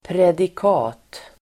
Uttal: [predik'a:t]